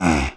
spawners_mobs_mummy_hit.3.ogg